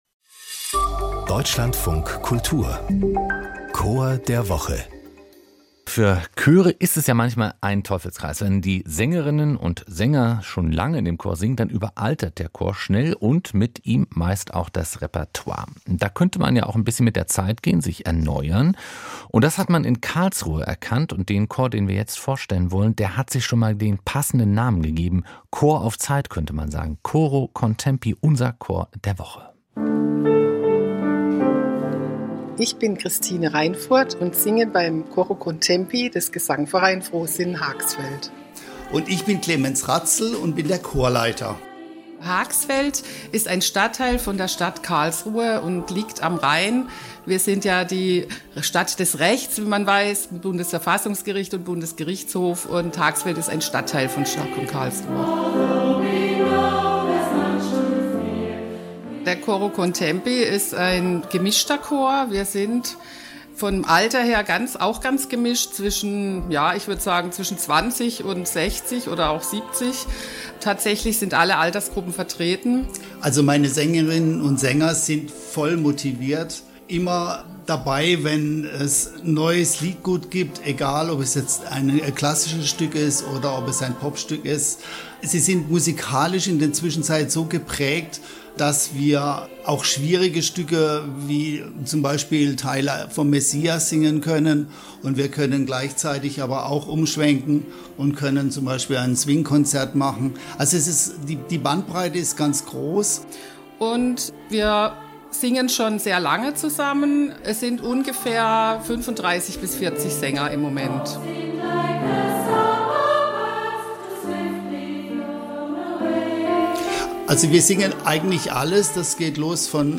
Hier stellen wir Ihnen jede Woche einen Chor vor. Ob Schulchor, Kirchenchor, Kammerchor oder...